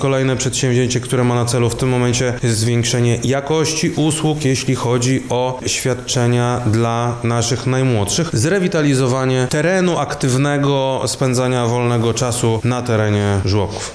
– mówi Krzysztof Komorski, Wojewoda Lubelski.